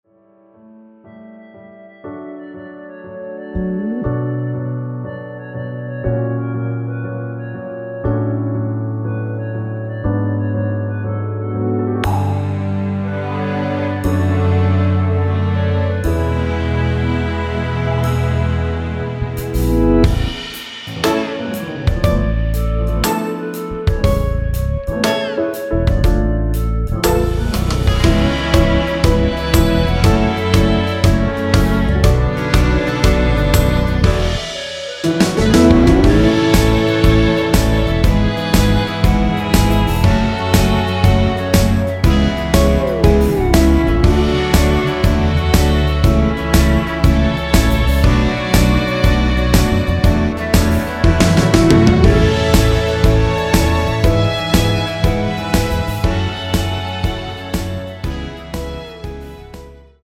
원키에서(-1)내린 멜로디 포함된 MR입니다.(미리듣기 확인)
앞부분30초, 뒷부분30초씩 편집해서 올려 드리고 있습니다.